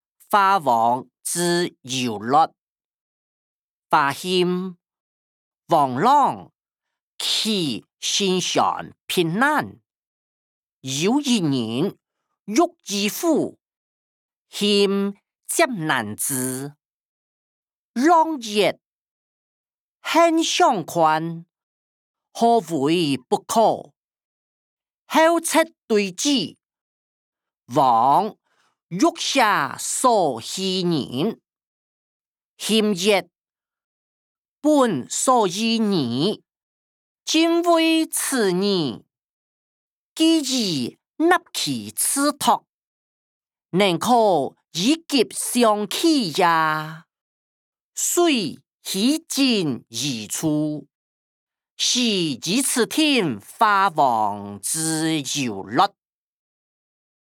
小說-華、王之優劣音檔(大埔腔)